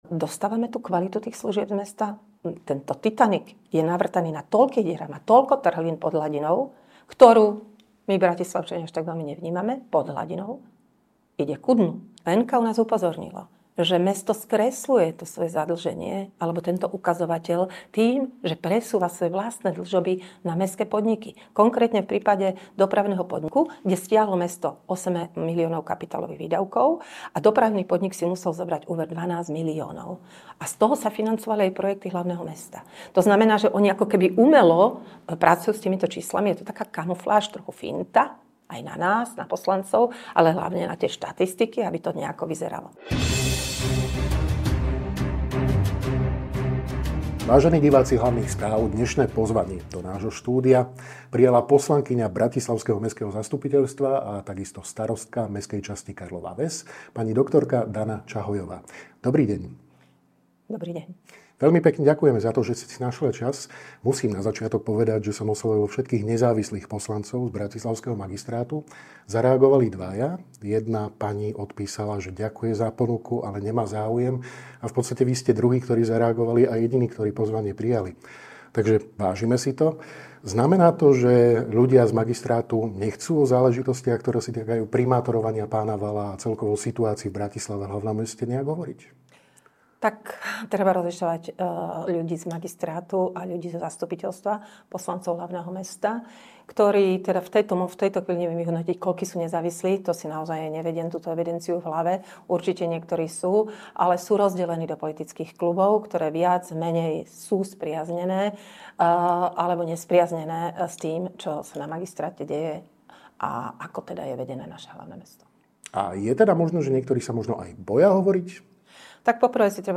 O týchto, ale aj mnohých iných témach sme sa rozprávali s poslankyňou bratislavského mestského zastupiteľstva, zároveň aj starostkou mestskej časti Karlova Ves, MUDr. Danou Čahojovou.
Viac vo video rozhovore.